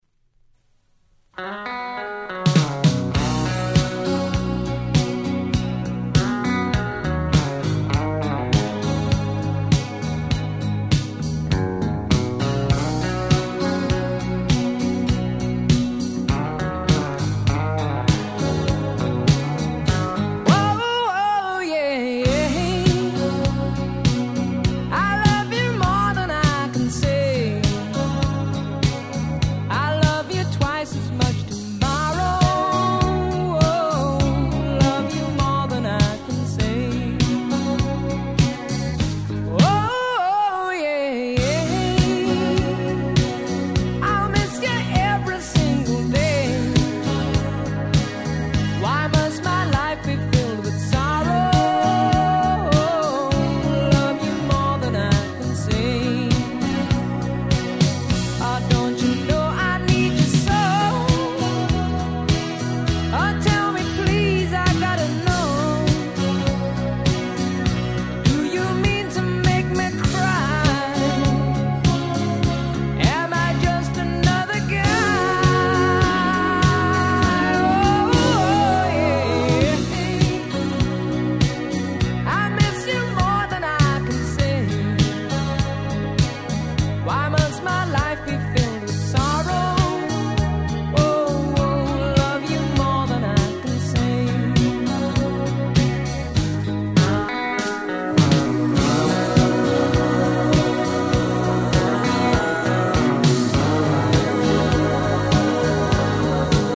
80' Super Hit Disco/Pops !!
こちらの国内PromoにはレアなDisco Versionを収録！！